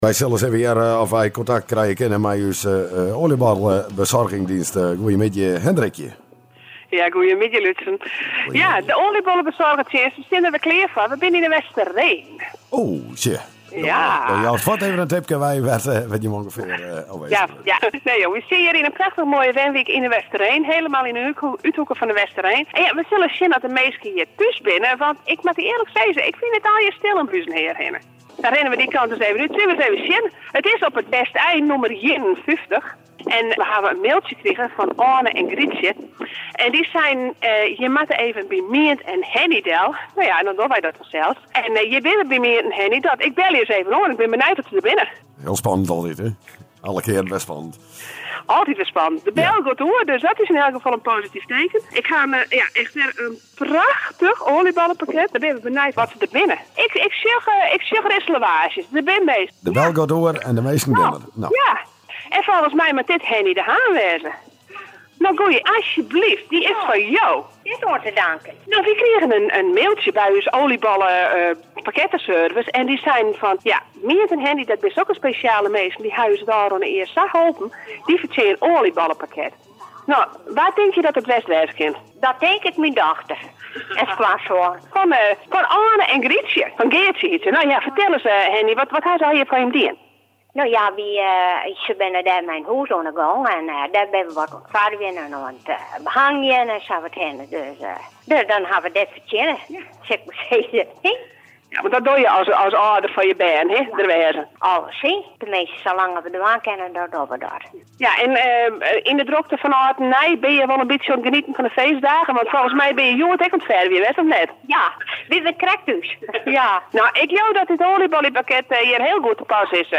Het derde oliebollenpakket van Bakkerij Eddy de Jong werd woensdagmiddag tijdens de ParadiseFM marathon uitgereikt in De Westereen.